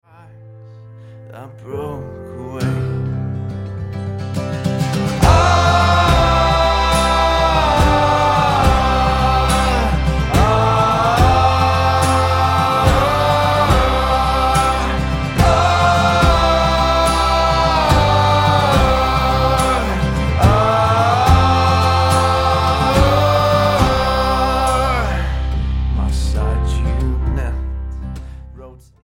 folk rock band